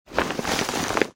Звуки сумки, ранца